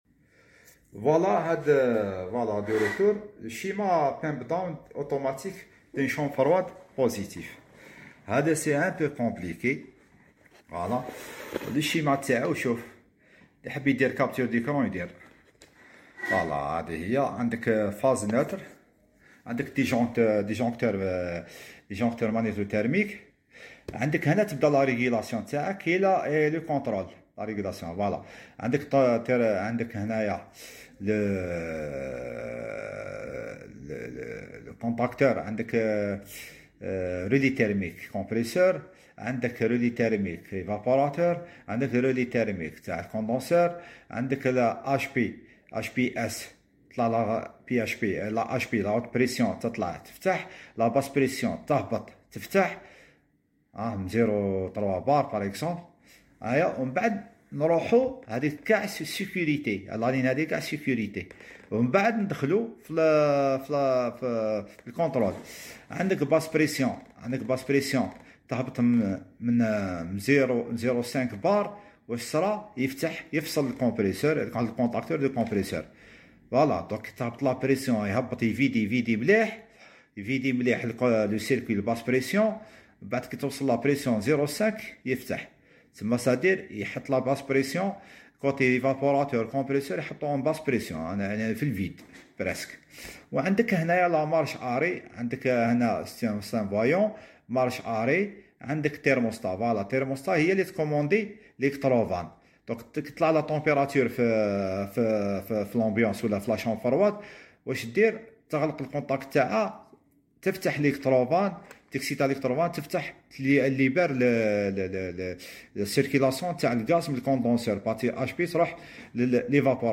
Climatisation centrale HVAC Régulation Pump sound effects free download